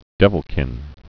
(dĕvəl-kĭn)